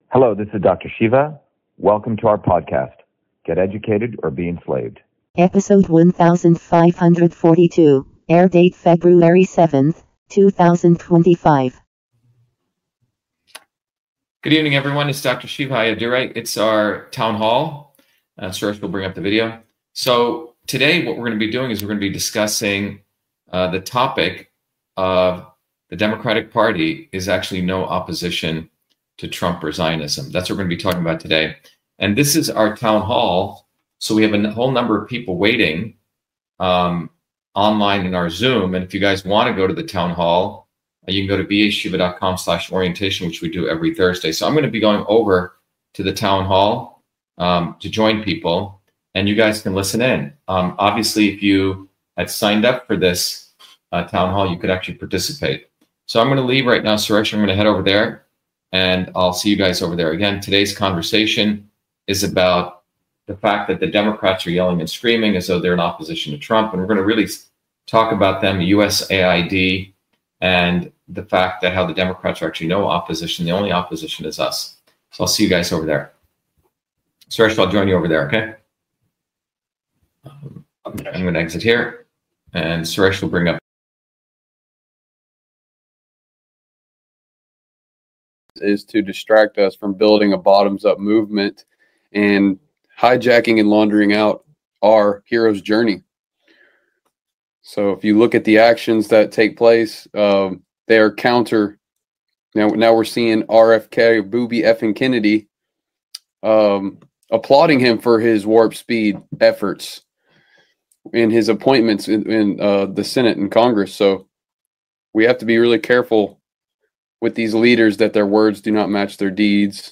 In this interview, Dr.SHIVA Ayyadurai, MIT PhD, Inventor of Email, Scientist, Engineer and Candidate for President, Talks about The Democratic Party is NO Opposition to Trump and Zionism